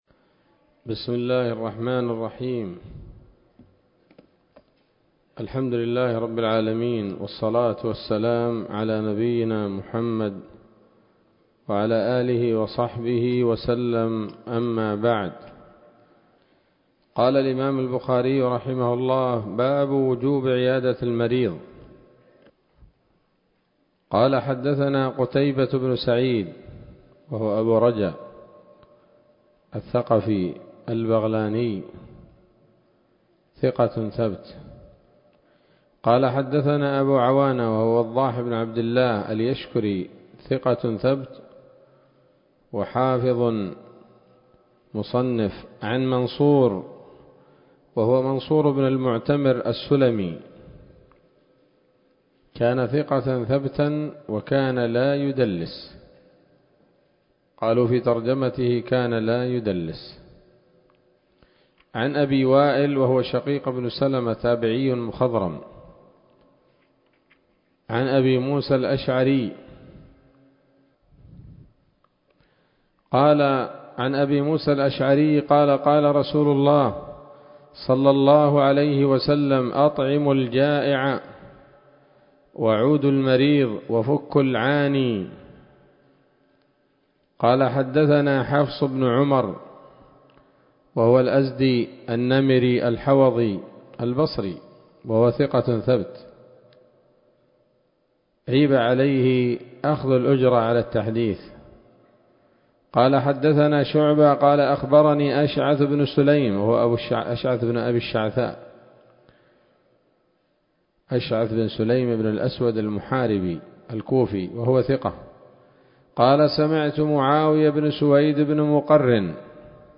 الدروس العلمية شروح الحديث صحيح الإمام البخاري كتاب المرضى من صحيح البخاري